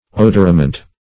Search Result for " odorament" : The Collaborative International Dictionary of English v.0.48: Odorament \O"dor*a*ment\, n. [L. odoramentum.